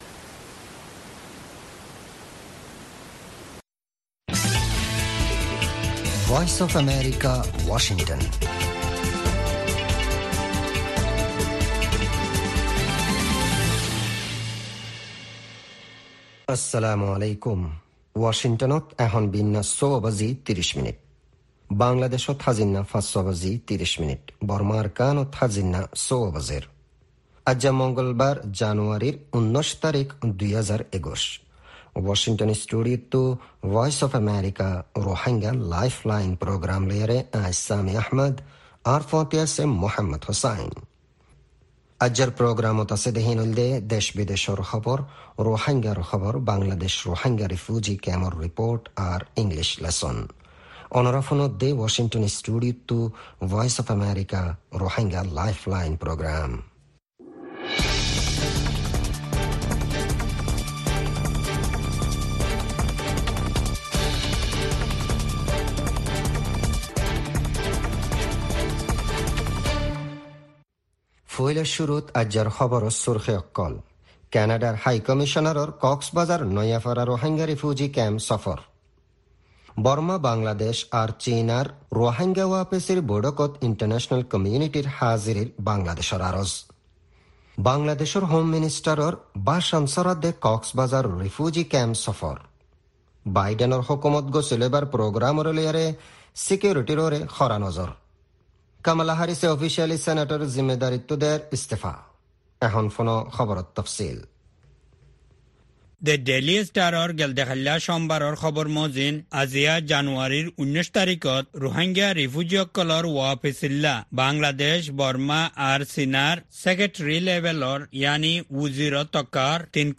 News Headlines